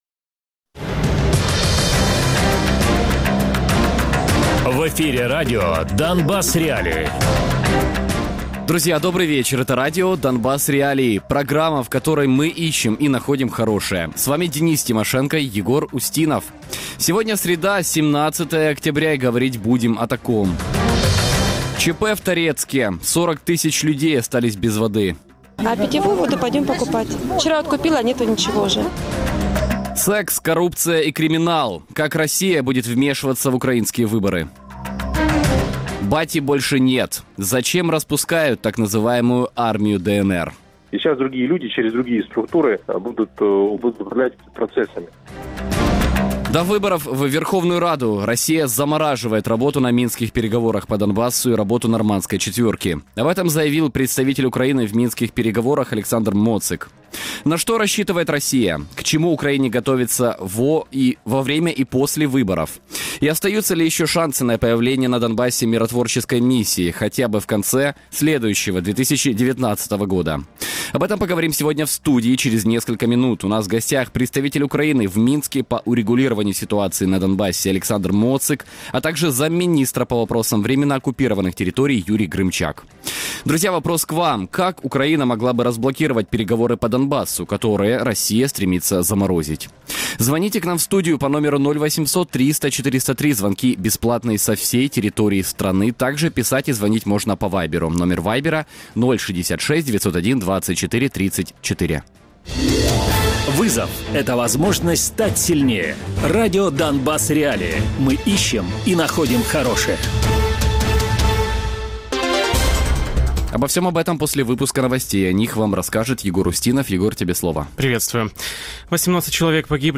Гість: Олександр Моцик - представник України в робочій підгрупі з політичних питань ТКГ в Мінську щодо врегулювання ситуації на Донбасі. Радіопрограма «Донбас.Реалії» - у будні з 17:00 до 18:00. Без агресії і перебільшення. 60 хвилин про найважливіше для Донецької і Луганської областей.